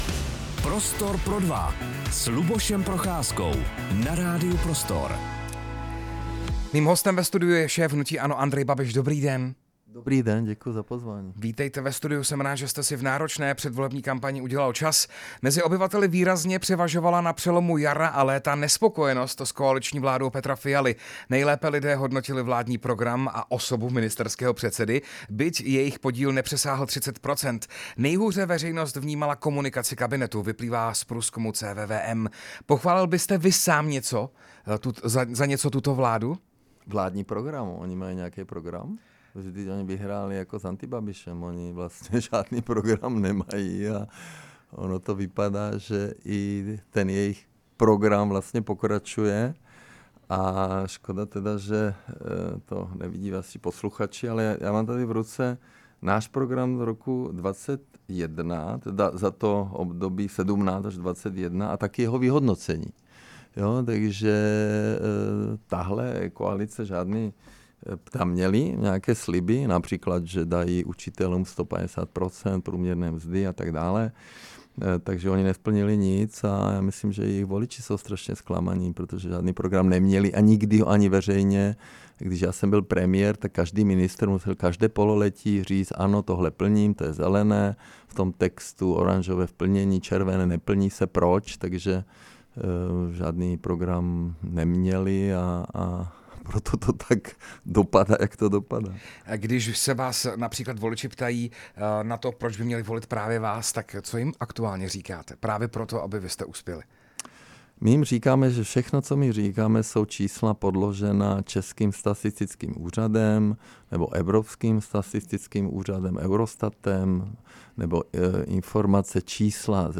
Pondělním hostem Prostoru pro dva byl šéf hnutí ANO Andrej Babiš. V rozhovoru zkritizoval vládu Petra Fialy - hlavně pokud jde o bitcoinovou kauzu.
Rozhovor s předsedou hnutí ANO Andrejem Babišem